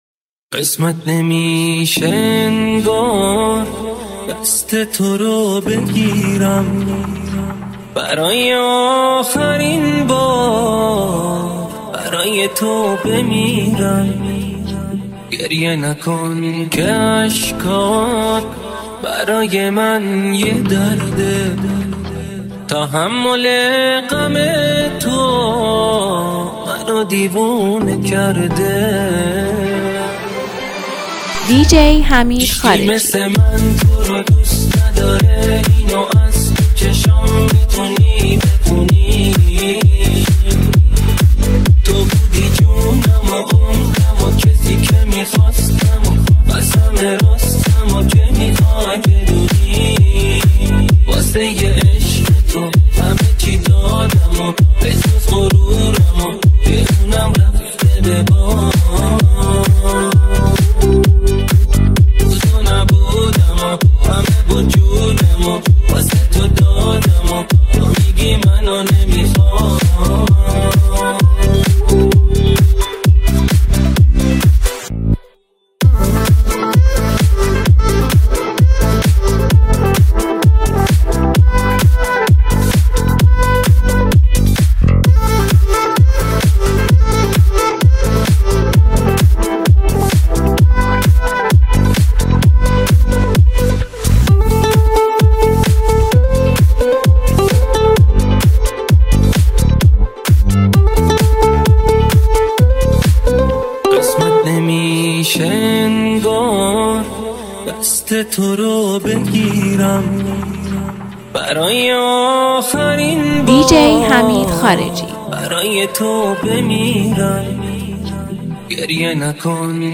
با فضایی پاییزی و دلنشین